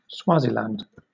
3. ^ /ˈswɑːzilænd/
SWAH-zee-land